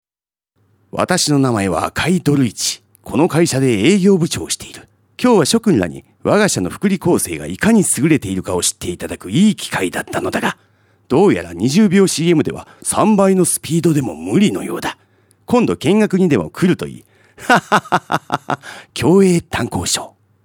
ラジオCM制作
有名アニメキャラクターを彷彿させる語り口で、壮大な世界観をラジオCMで表現。